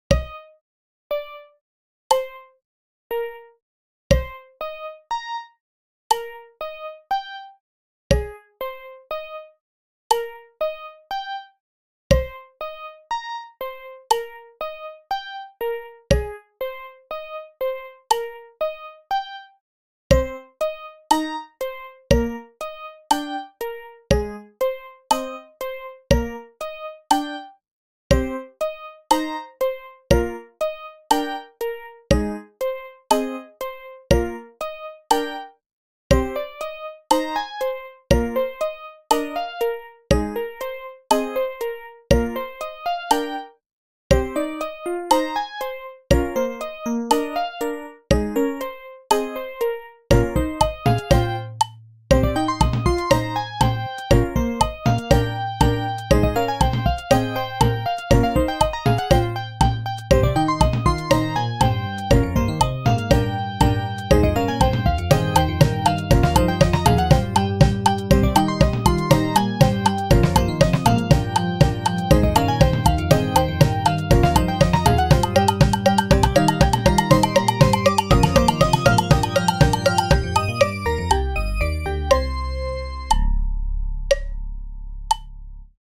build-up fast-paced